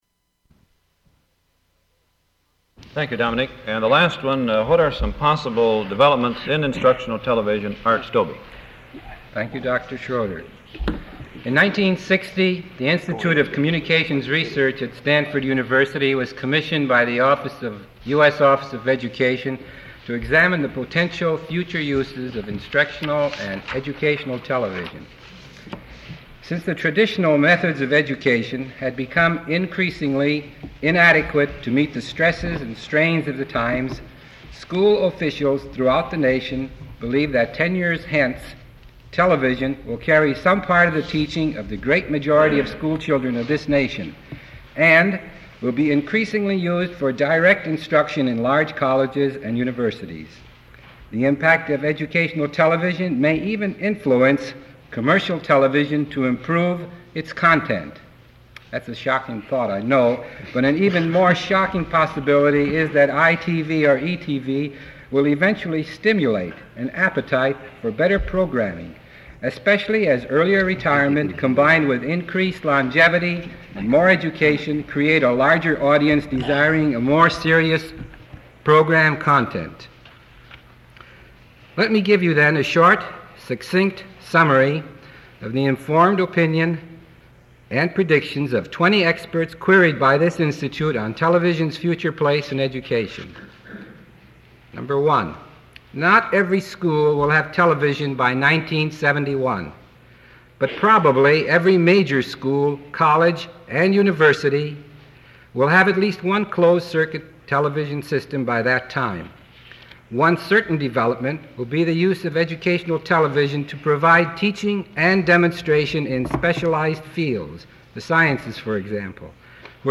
[00:07:34] Panel begins Q&A period [00:08:18] Man from the audience asks question comparing advantages of color and film
[00:16:40] Applause from audience
Form of original Open reel audiotape